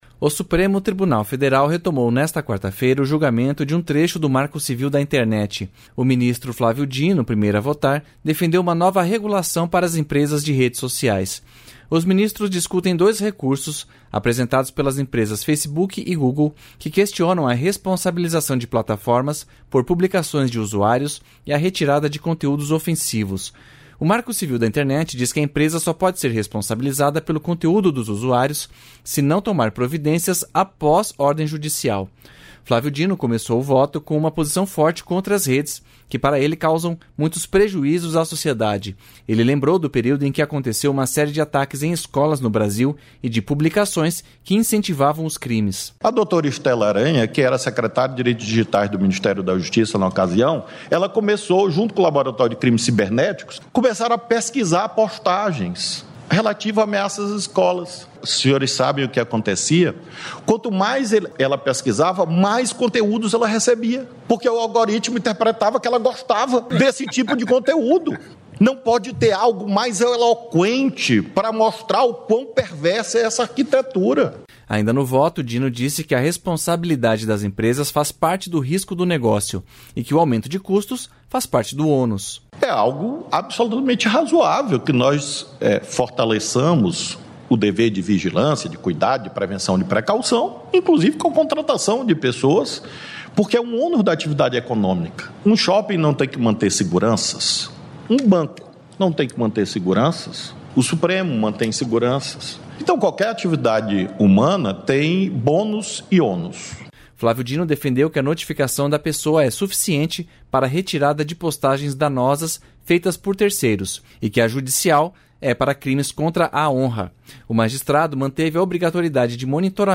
Repórter Rádio Nacional